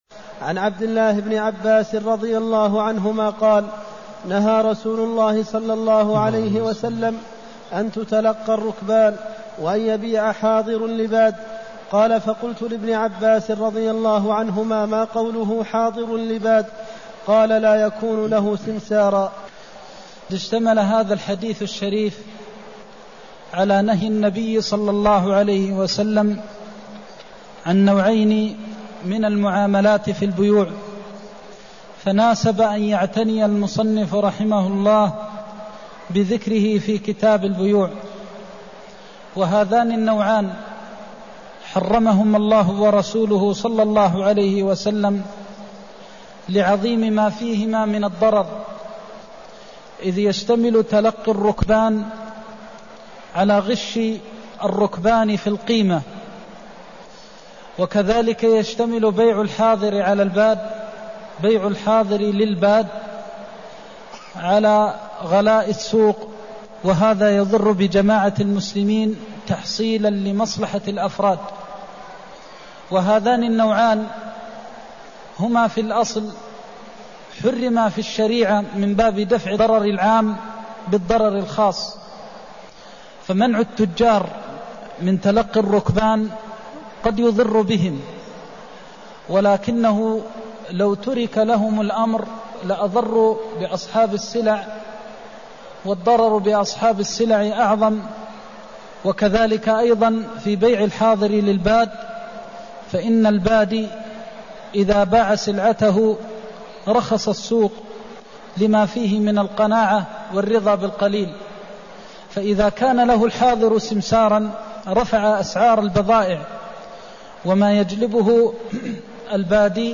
المكان: المسجد النبوي الشيخ: فضيلة الشيخ د. محمد بن محمد المختار فضيلة الشيخ د. محمد بن محمد المختار نهيه عن بيع الحاضر للباد (249)